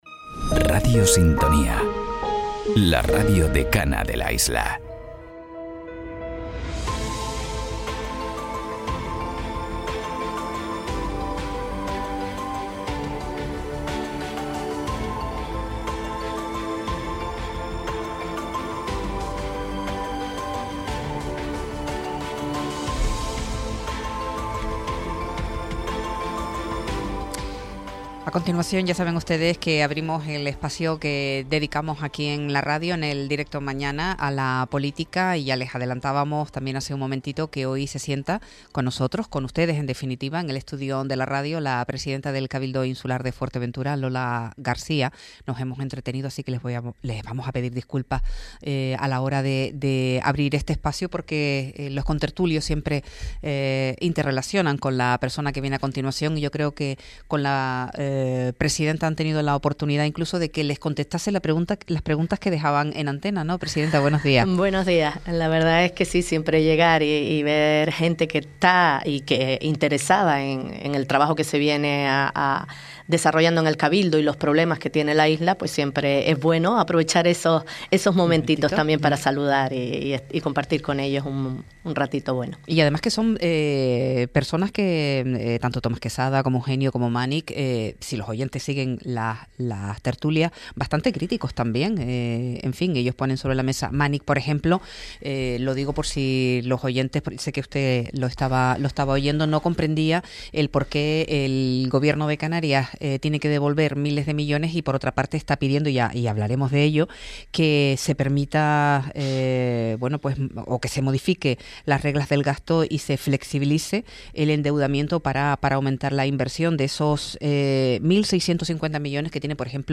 Entrevista a Lola García, presidenta del Cabildo de Fuerteventura - 15.04.26 - Radio Sintonía
La presidenta del Cabildo de Fuerteventura, Lola García visita los estudios de la radio esta mañana.